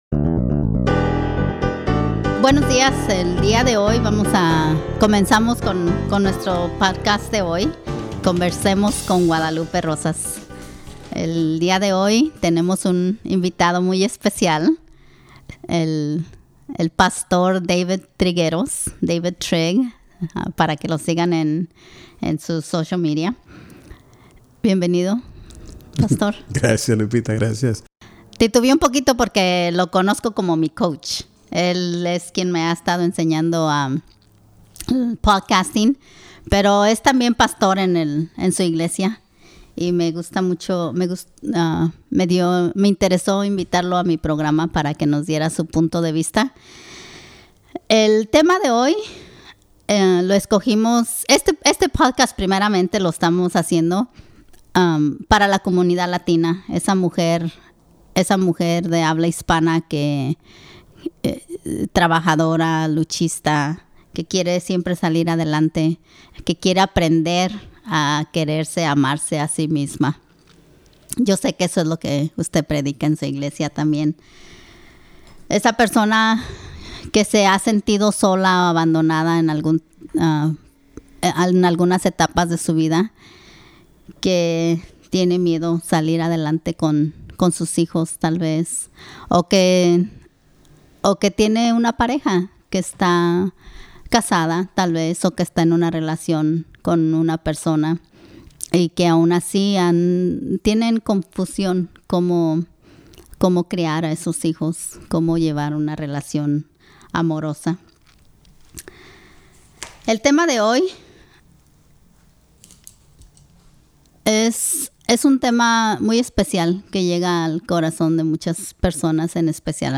En este programa: ¿Debería de quedarse con su pareja por los hijos? En esta entrevista, hablamos de los pros y cons de quedarte o separarte de tu pareja.